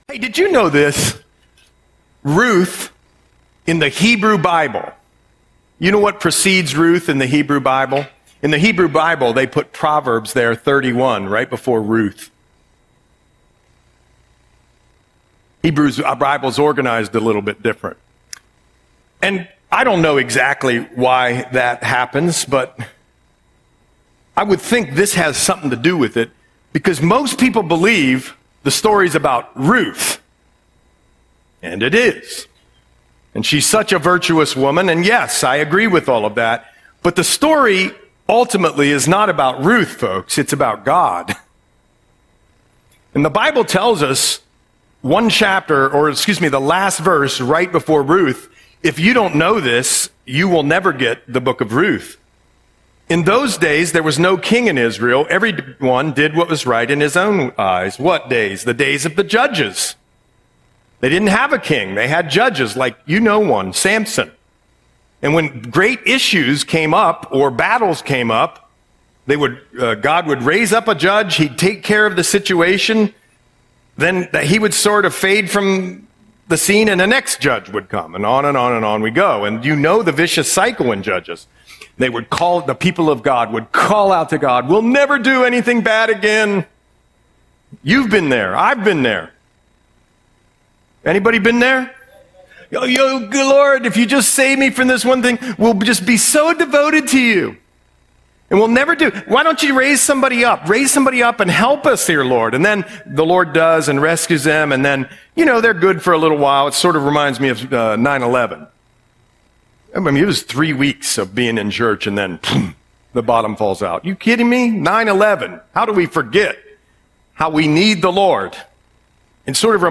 Audio Sermon - September 7, 2025